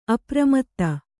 ♪ apramatta